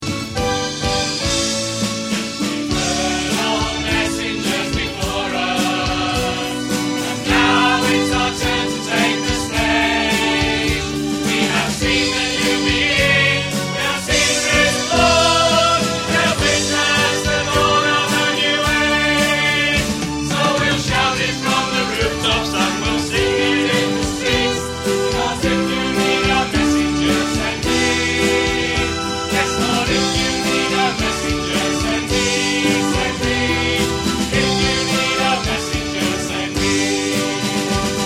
Part 2; sung by the COMPANY
MPEG file of the third verse and chorus (595kb)